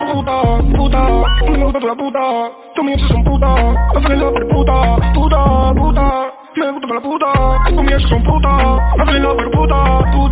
failsound.mp3